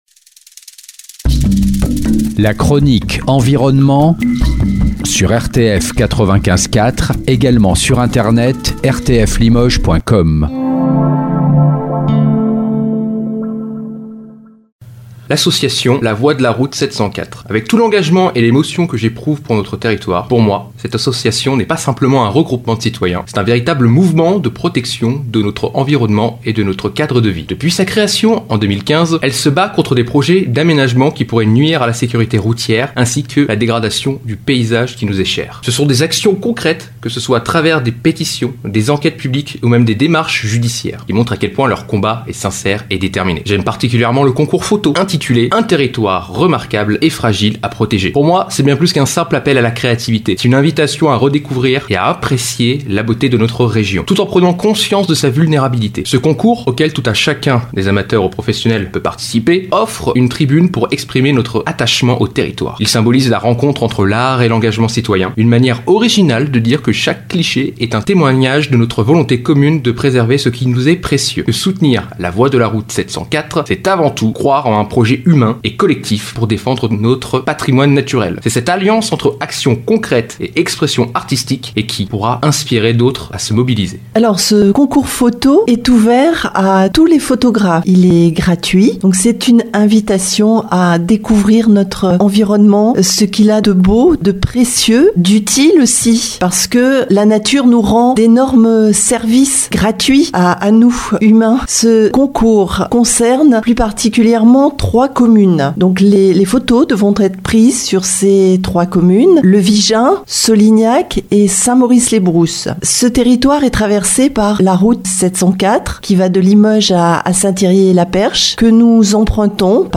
chronique et interview